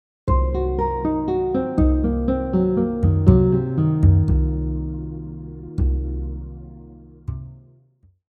Next, we have a descending arpeggio pattern that skips one note after every chord tone.
half-diminished-arpeggio-example-2.mp3